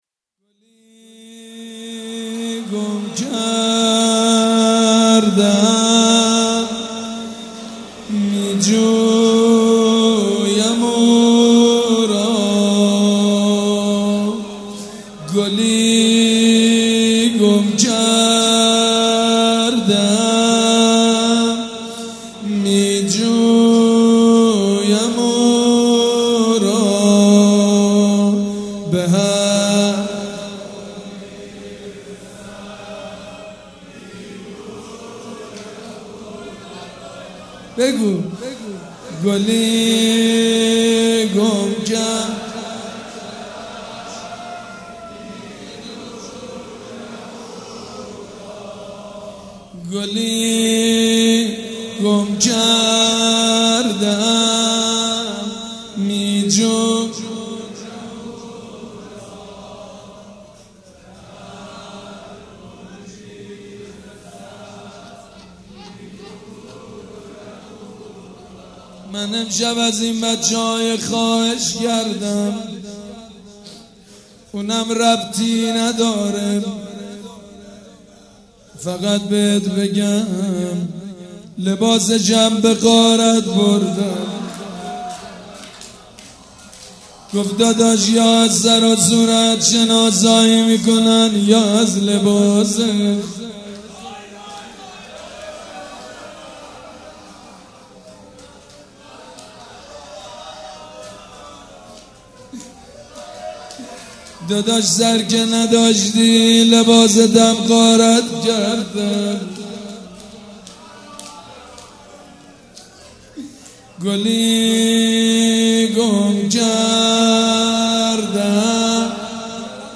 شب یازدهم محرم الحرام‌
روضه
مراسم عزاداری شب شام غریبان